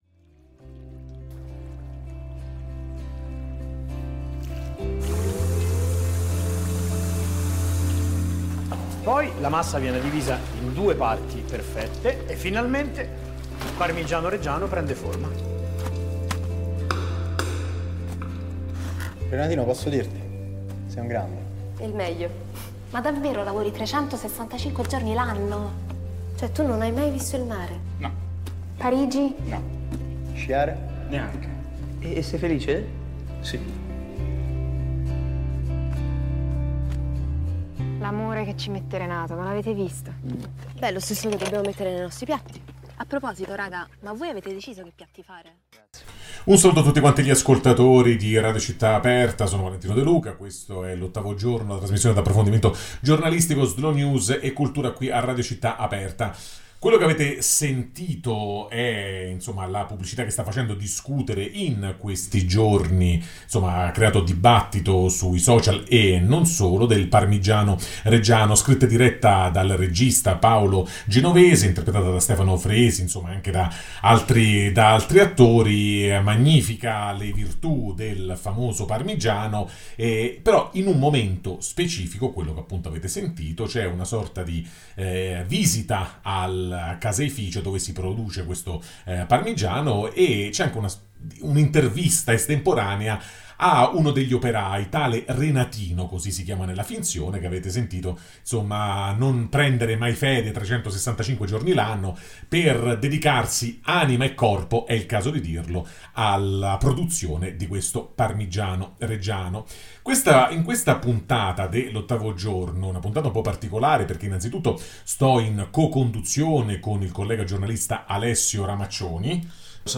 La felicità dei Renatino: Intervista a Christian Raimo [PODCAST] | Radio Città Aperta